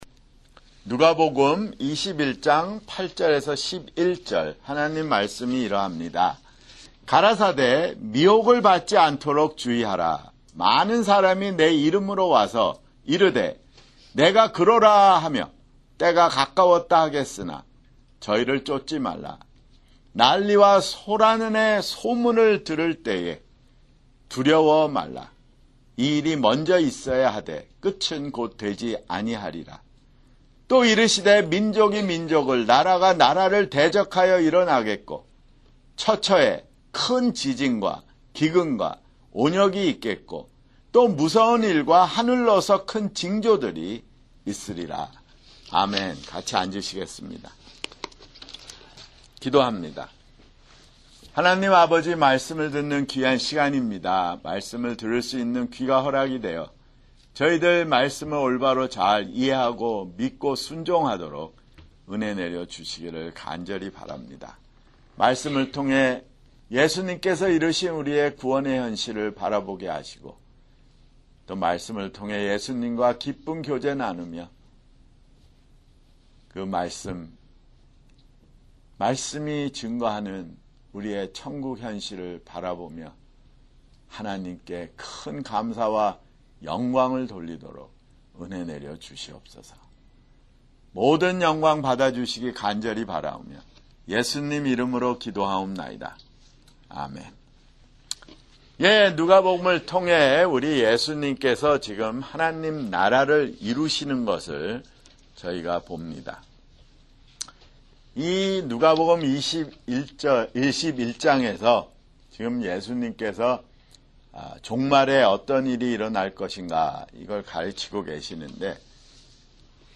[주일설교] 누가복음 (140)